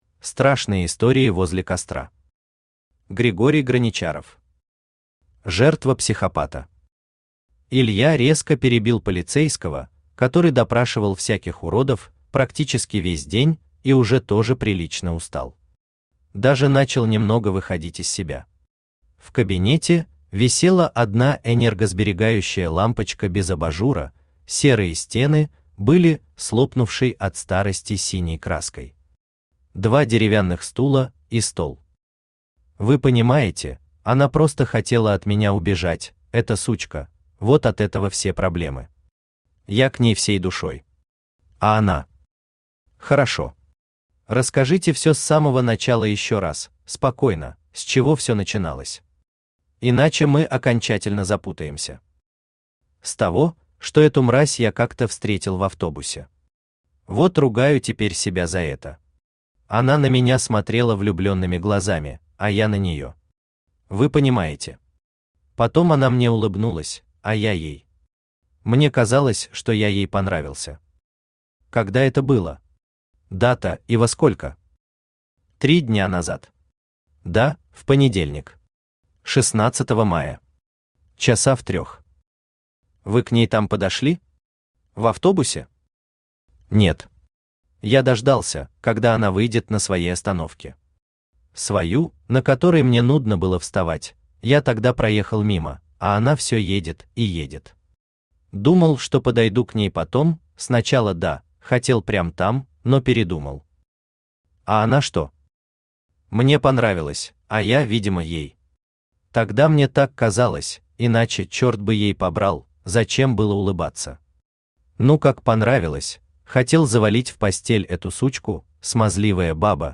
Аудиокнига Страшные истории возле костра | Библиотека аудиокниг
Читает аудиокнигу Авточтец ЛитРес